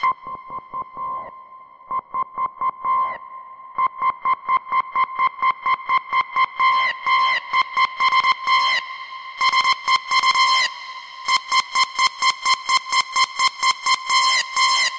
标签： 支付宝 到账 提示音 一个亿 转账
声道立体声